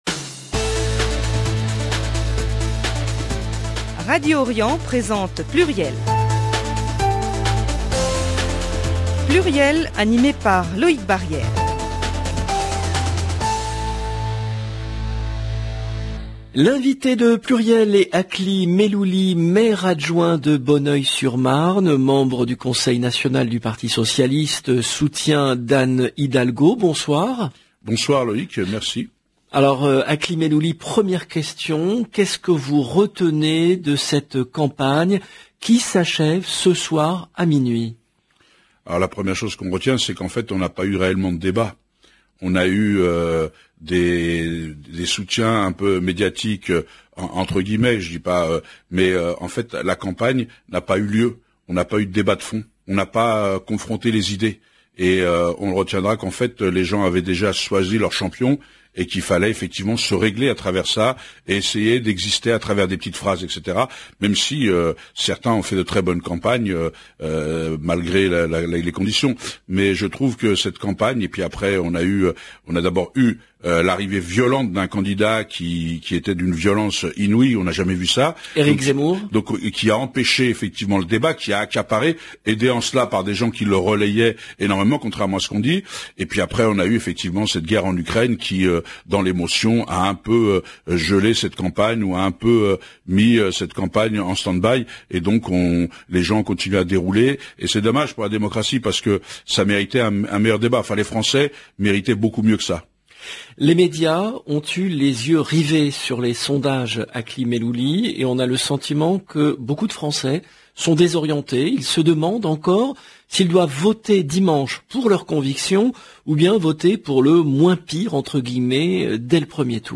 L’invité de PLURIEL est Akli Mellouli , Maire-adjoint de Bonneuil-sur-Marne, membre du Conseil national du Parti Socialiste, soutien d’Anne Hidalgo